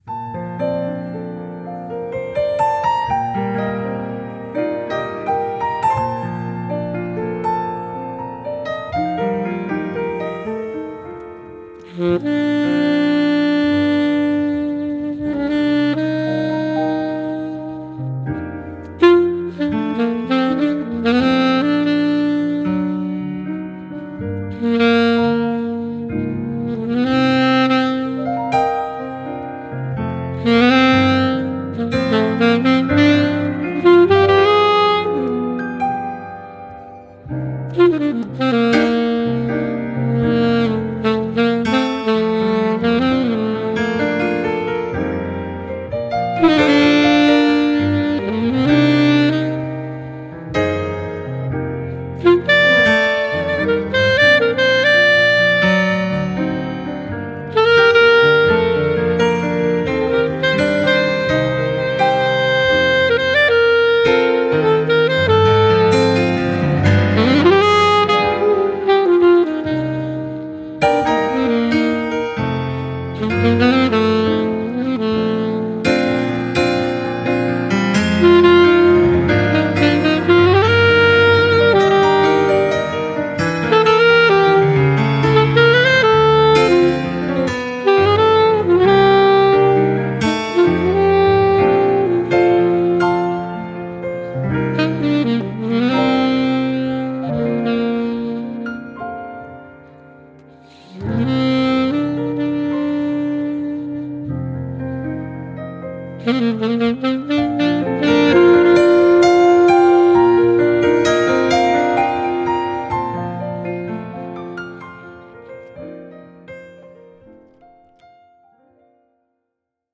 zamba
No sé ni donde puse el micrófono, pero no importa.